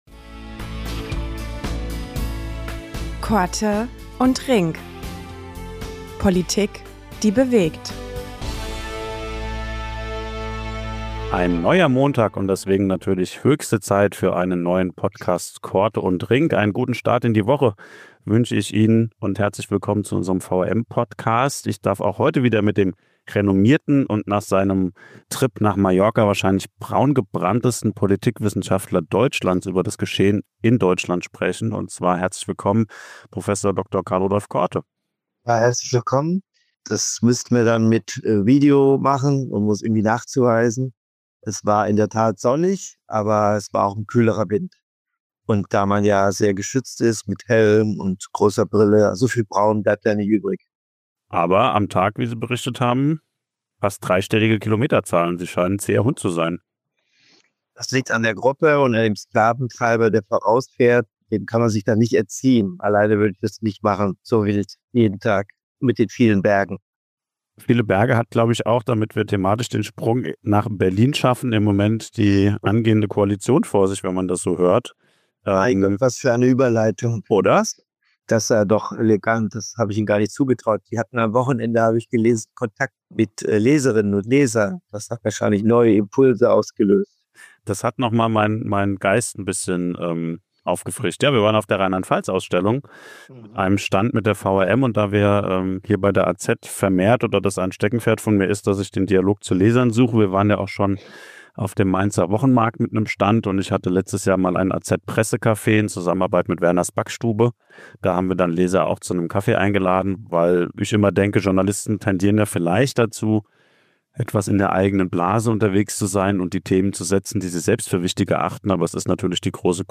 Politikwissenschaftler Prof. Dr. Karl-Rudolf Korte